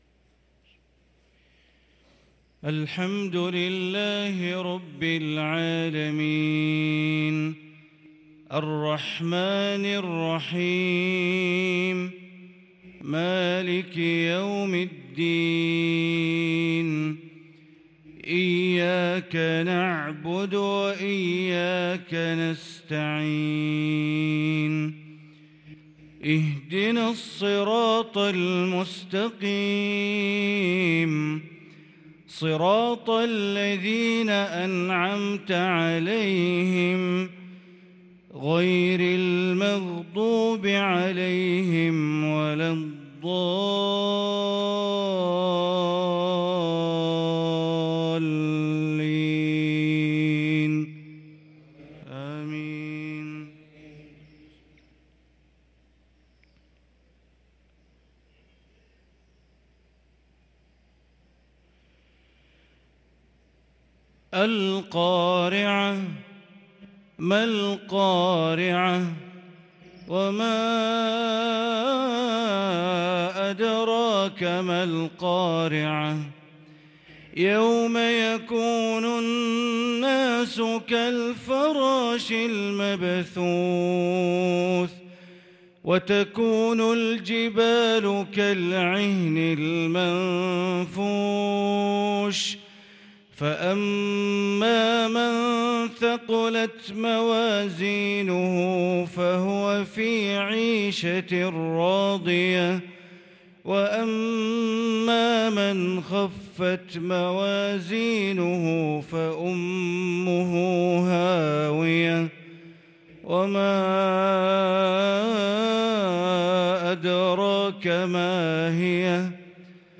صلاة المغرب للقارئ بندر بليلة 25 ربيع الآخر 1444 هـ